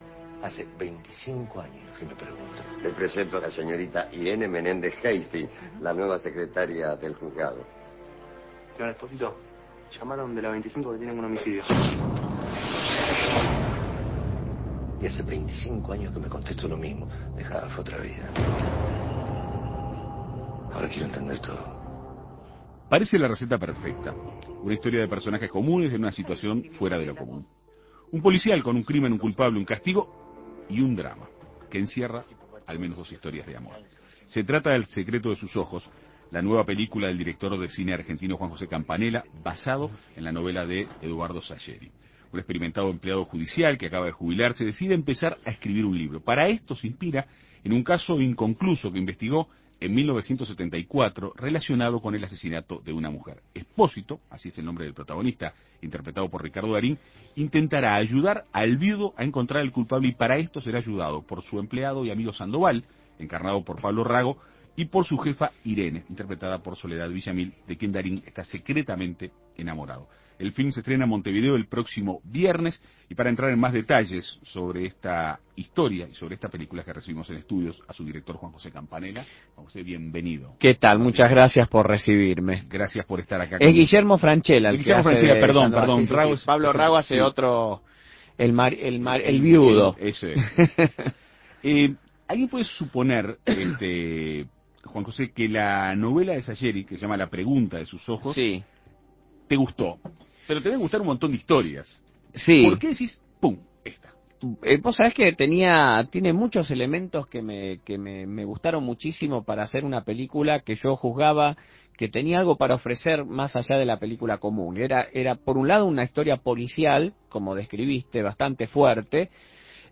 Para conocer detalles de esta película, En Perspectiva Segunda Mañana dialogó con su director, Juan José Campanella.